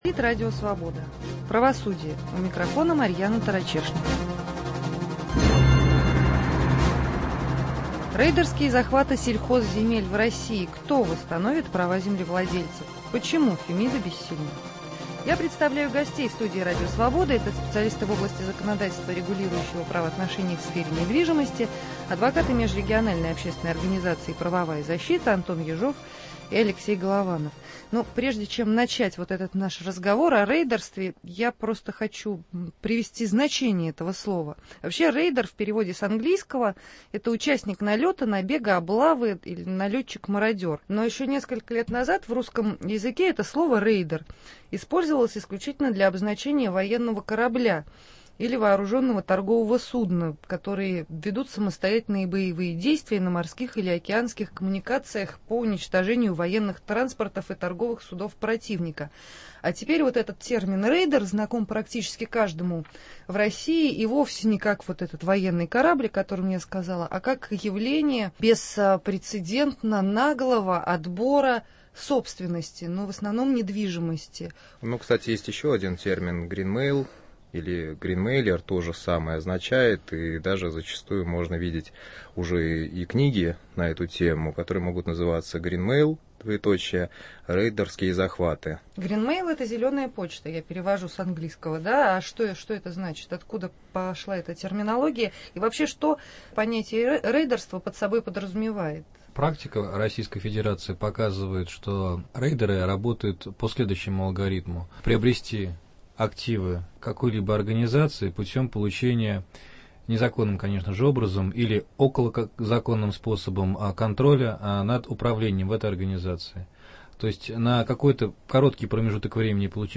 В студии Радио Свобода, специалисты в области законодательства, регулирующего правоотношения в сфере недвижимости.